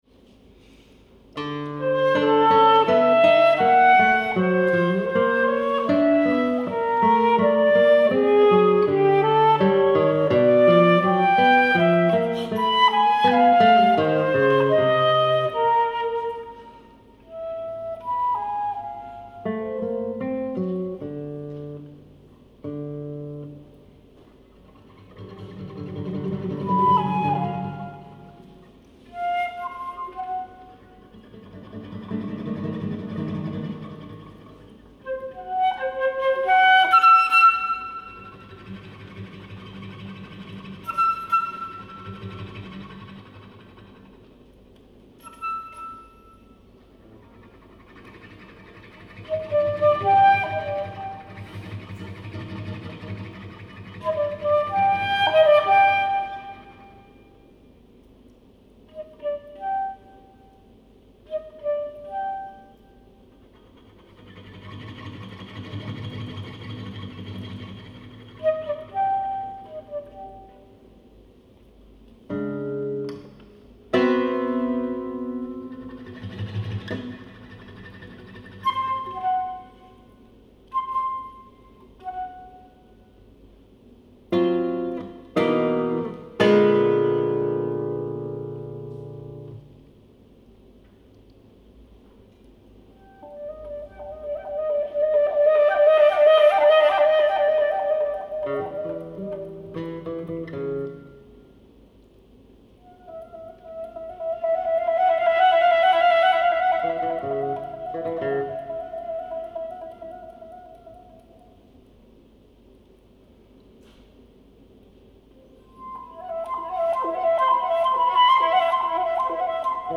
Con Spazio for Flute and Guitar (2012)
Fragmentation, transition and space are important structural elements in this piece.
Recorded at soundSCAPE festival, Maccagno, Italy.
Flute
Guitar